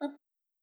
pegchamp/SFX/boop/E.wav at alpha
better sound effects